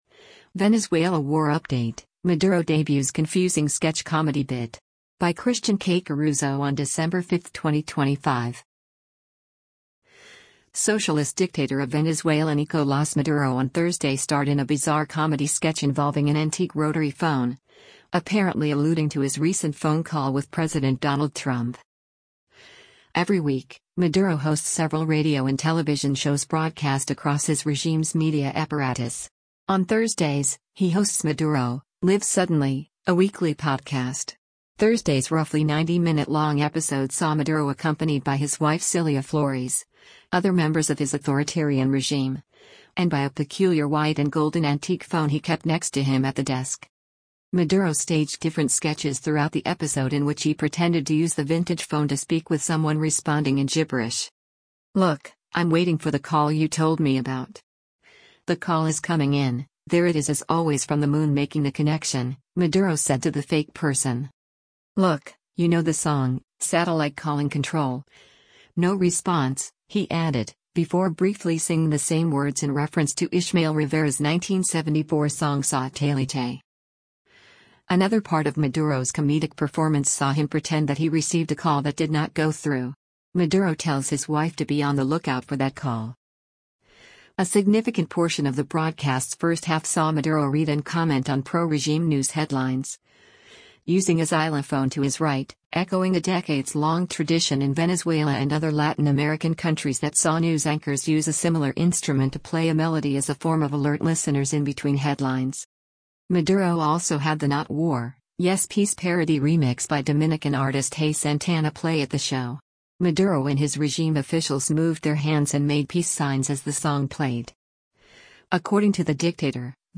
Maduro staged different “sketches” throughout the episode in which he pretended to use the vintage phone to speak with someone responding in “gibberish.”
A significant portion of the broadcast’s first half saw Maduro read and comment on pro-regime news headlines, using a xylophone to his right — echoing a decades long tradition in Venezuela and other Latin American countries that saw news anchors use a similar instrument to play a melody as a form of alert listeners in between headlines.